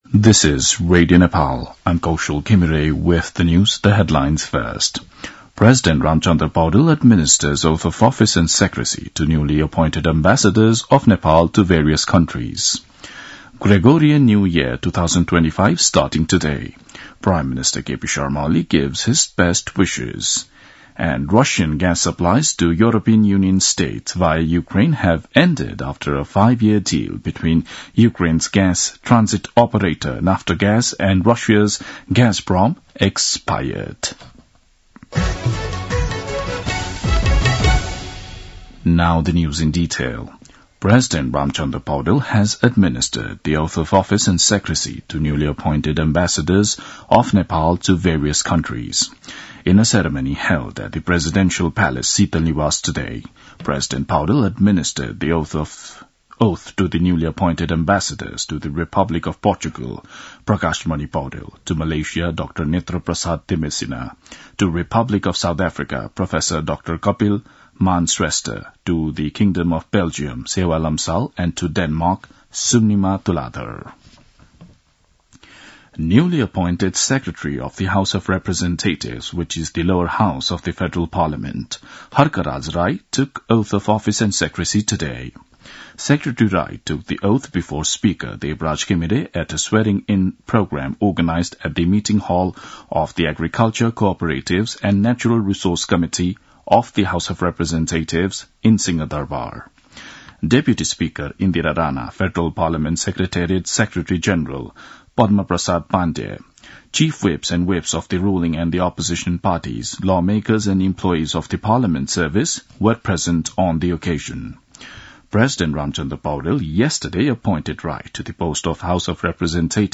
2-pm-english-news-.mp3